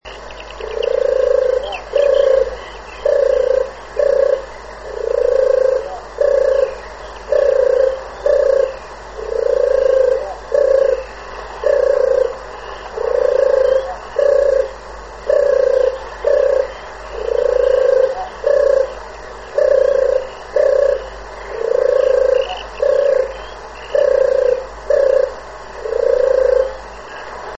Turkawka - Streptopelia turtur
głosy
turkawka.mp3